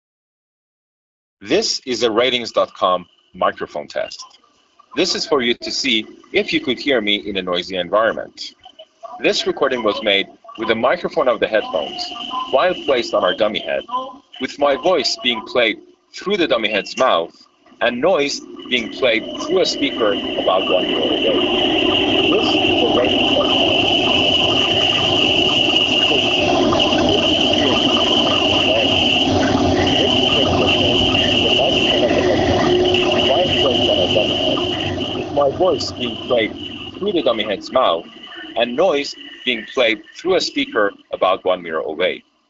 Speech + Subway Noise Audio Sample
With fluctuating background sounds like passing trains, your voice gets more or less drowned out.